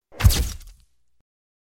Звуки вспышки
Эффектная вспышка фотоаппарата